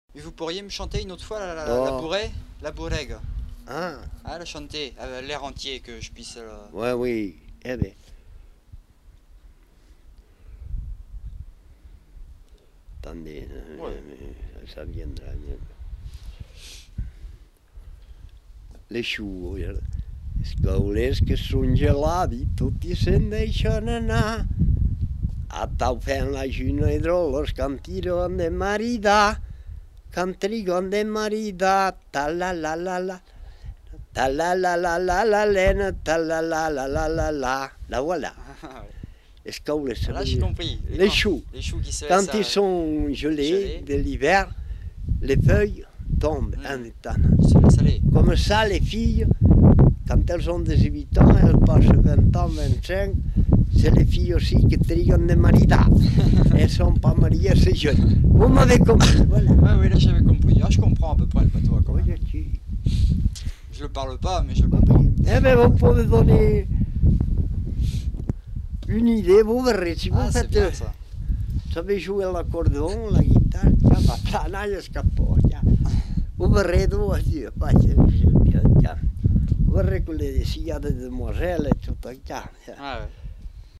Aire culturelle : Couserans
Genre : chant
Effectif : 1
Type de voix : voix d'homme
Production du son : chanté
Danse : bourrée d'Ariège